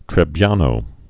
(trĕ-byänō, trĕbē-)